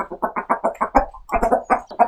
Added chicken sounds to the chickens.